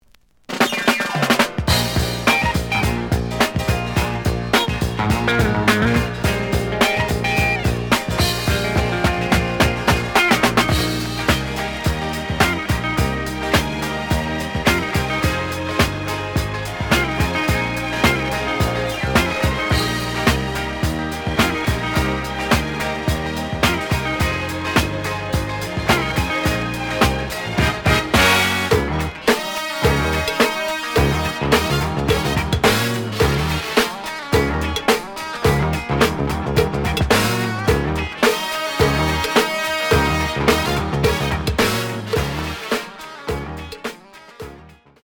試聴は実際のレコードから録音しています。
●Format: 7 inch
●Genre: Funk, 70's Funk